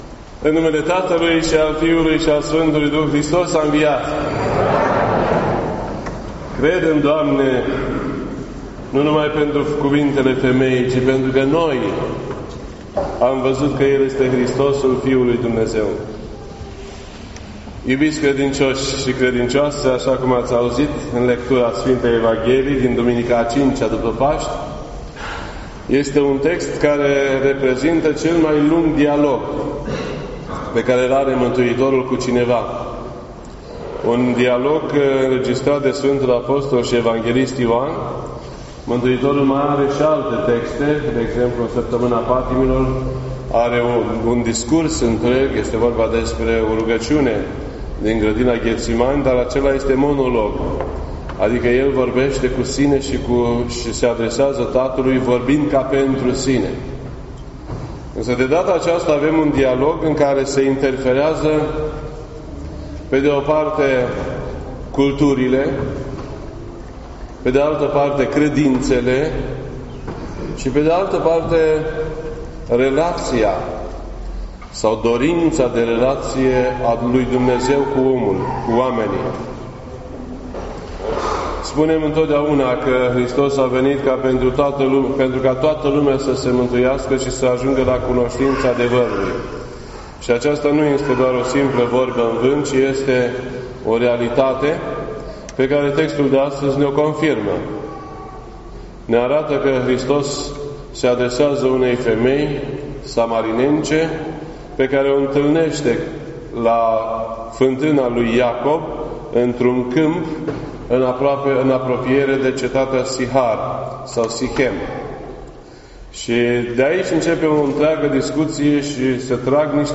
This entry was posted on Sunday, May 6th, 2018 at 1:36 PM and is filed under Predici ortodoxe in format audio.